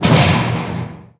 thud4.wav